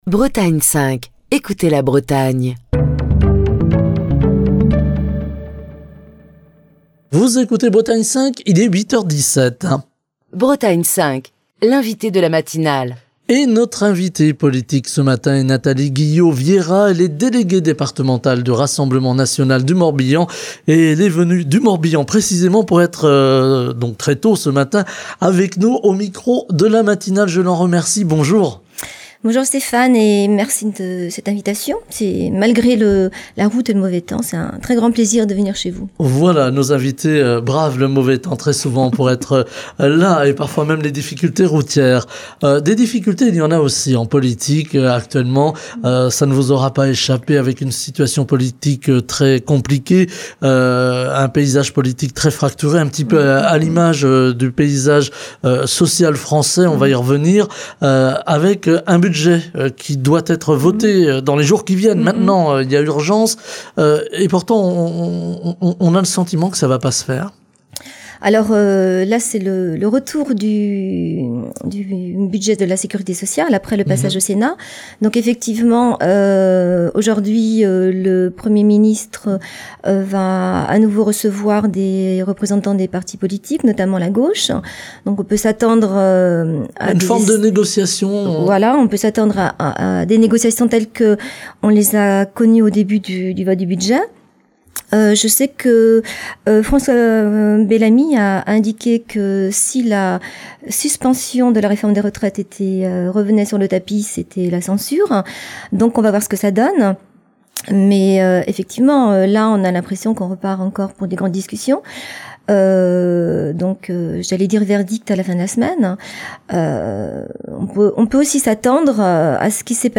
Écouter Télécharger Partager le podcast Facebook Twitter Linkedin Mail L'invité de Bretagne 5 Matin